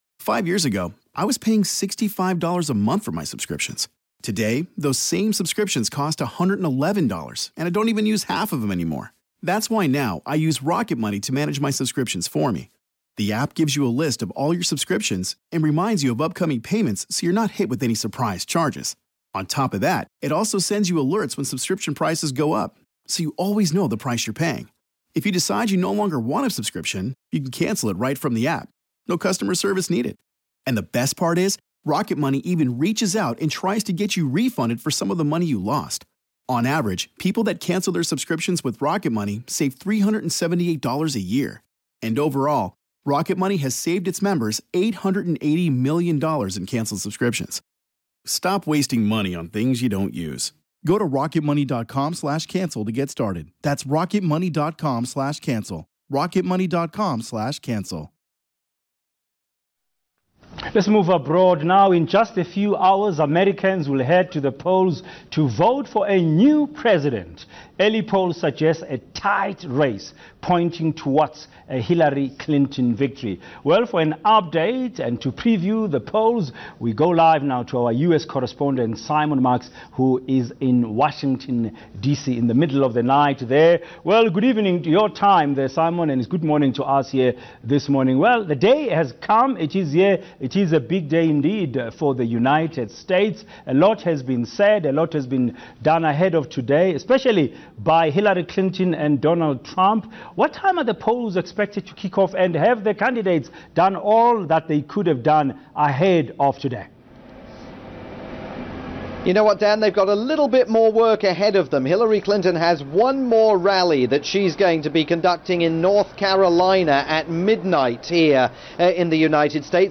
aired on South Africa's leading news channel ENCA.